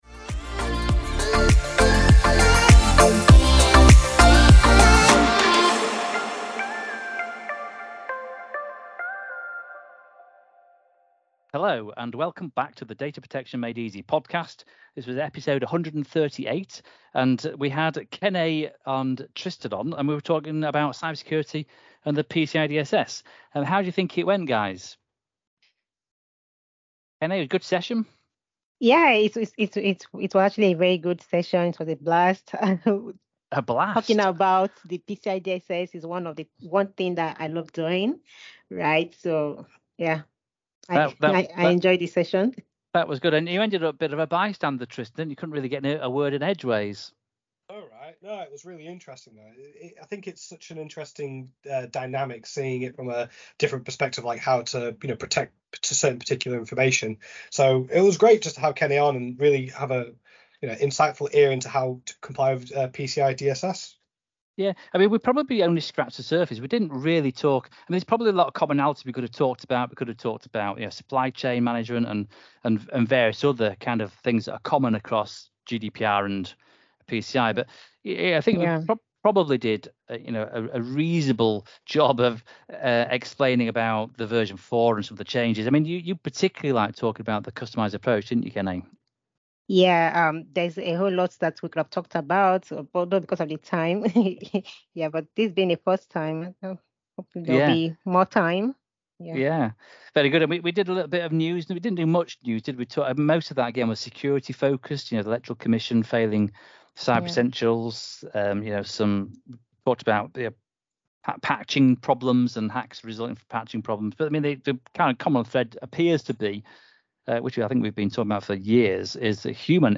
On this week's episode the trio unpick the latest changes to the PCI DSS and discuss how similarities can be drawn from the GDPR. This session was recorded Infront of a live audience of subscribers.